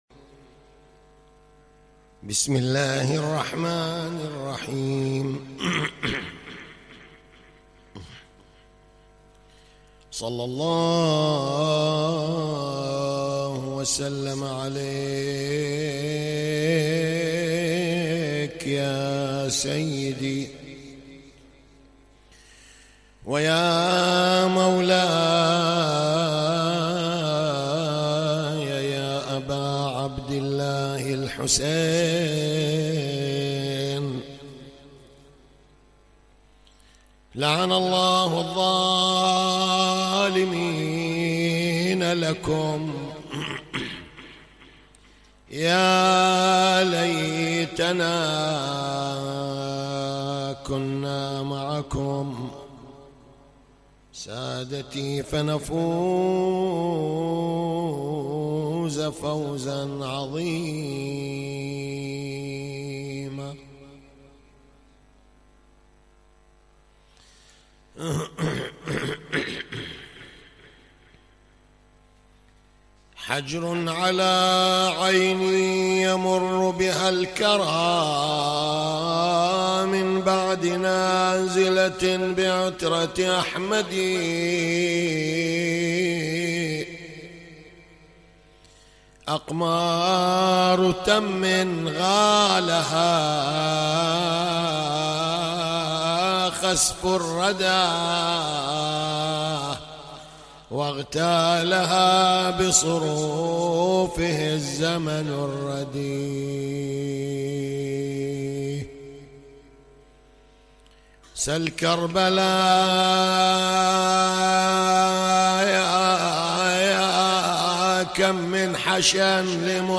تغطية صوتية: يوم التاسع من محرم الحرام 1439هـ